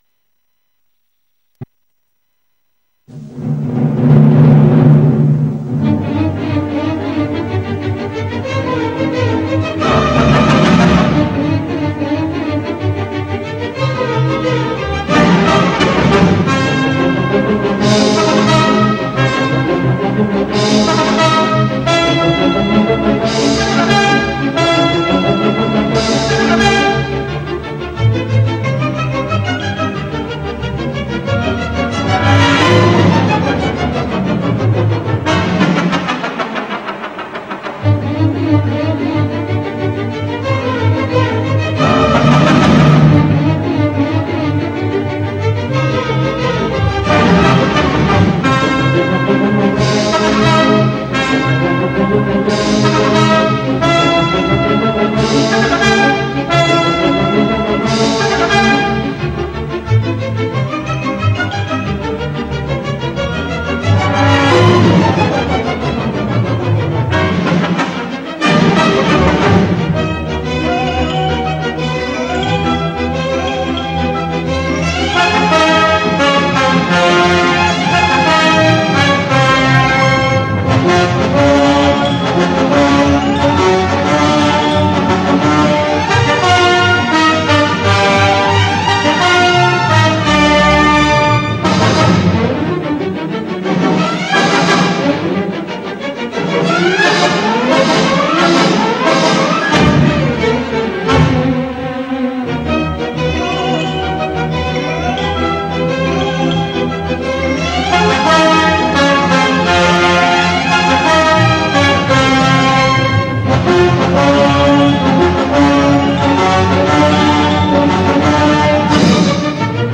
Theme tune: